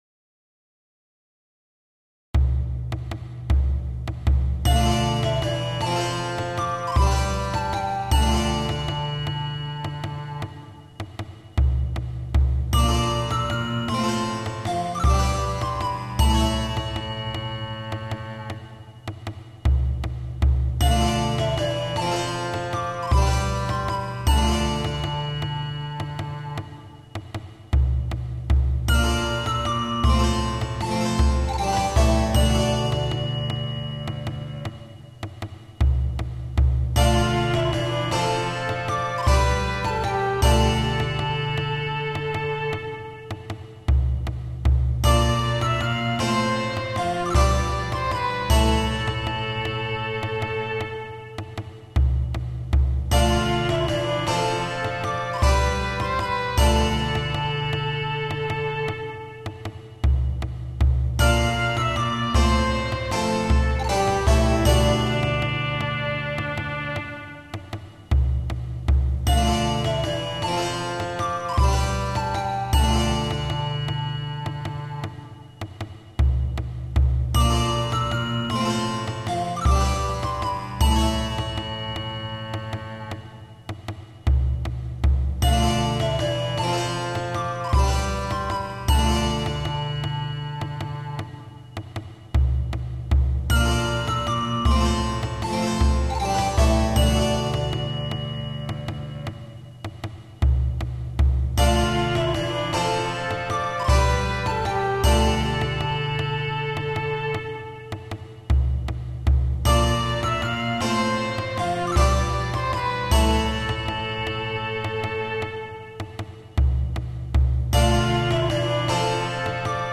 Advent carol in medieval style on a text by John A. Dalles.